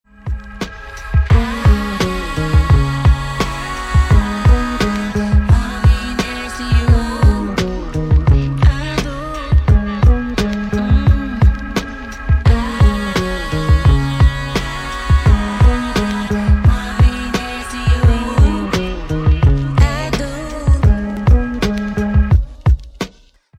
соул
медленные , спокойные , гитара , битовые , басы